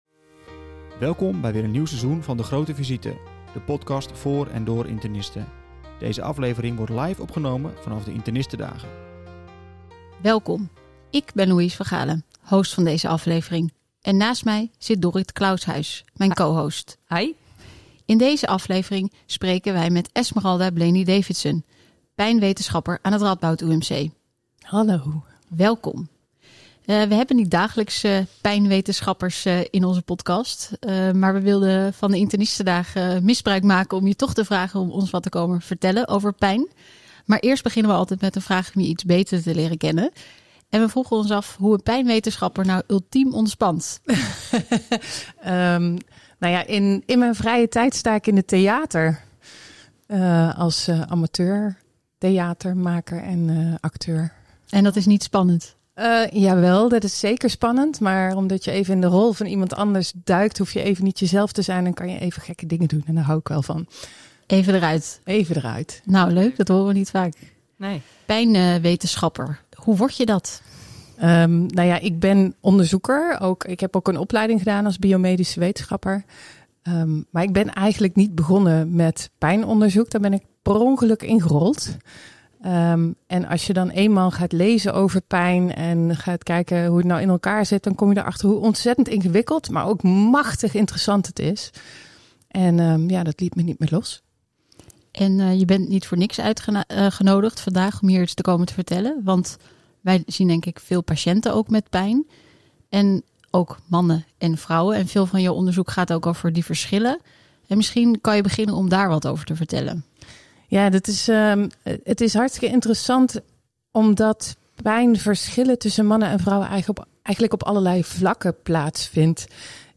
78. Pijnbeleving: in gesprek met een expert!